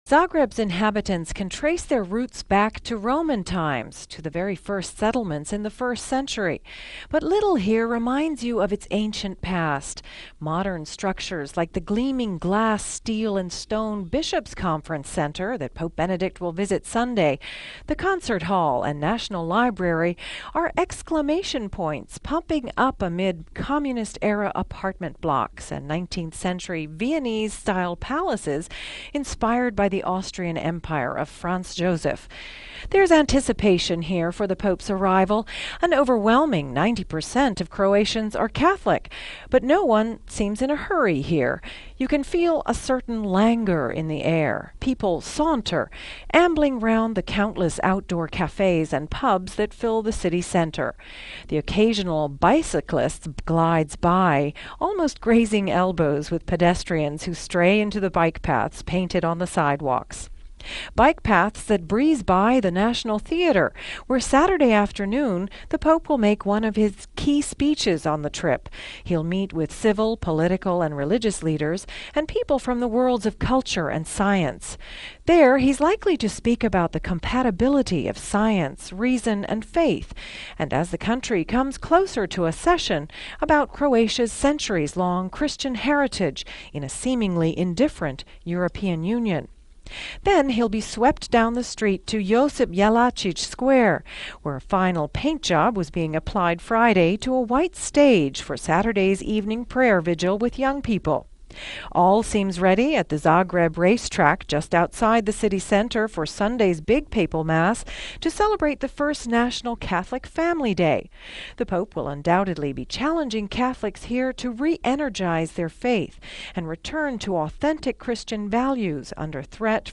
Our correspondent